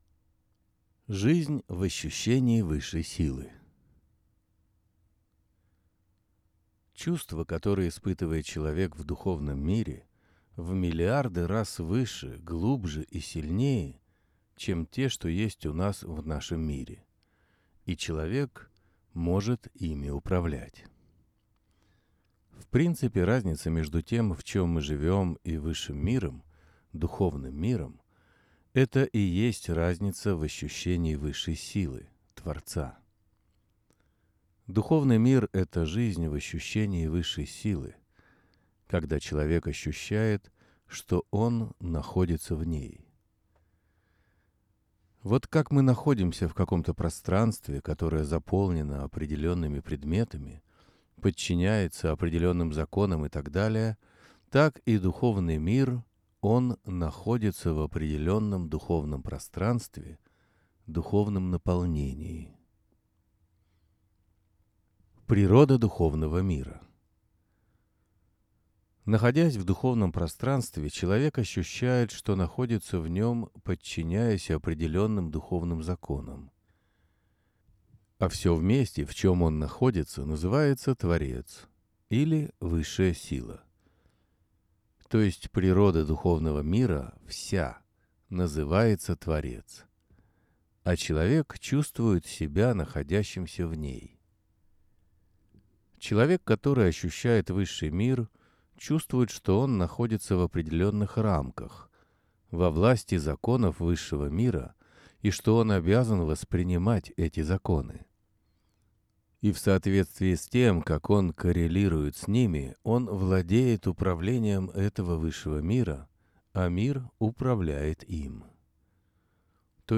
Аудиоверсия статьи